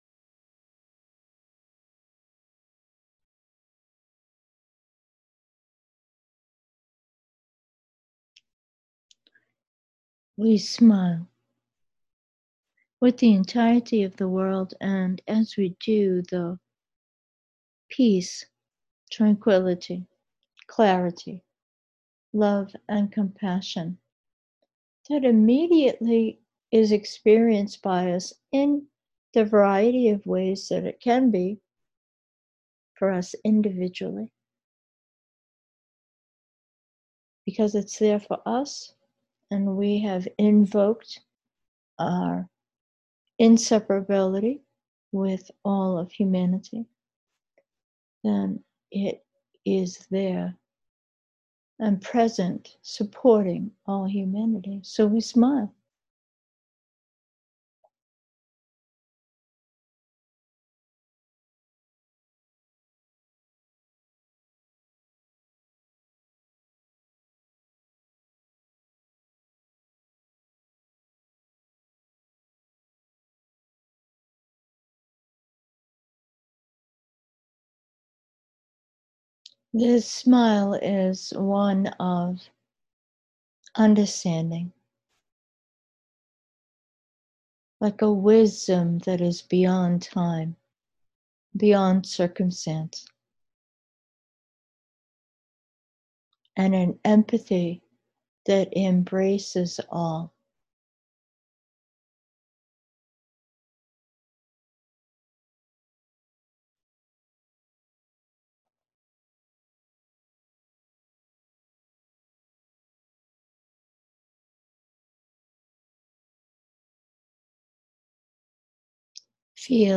Meditation: breathing as one